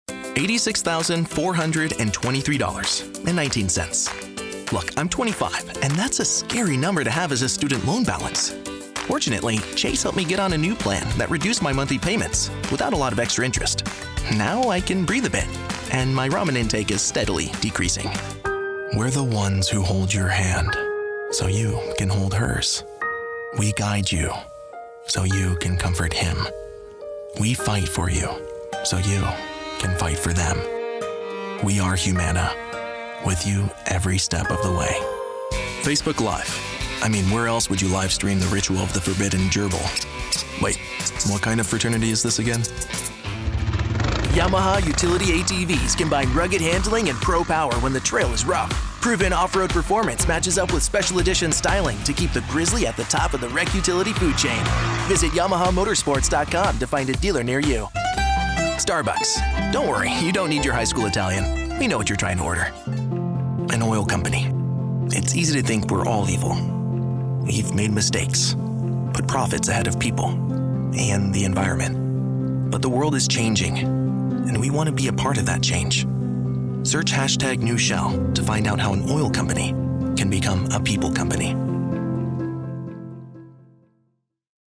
Commercial: Conversational, warm, straight-forward
Commercial (Werbung)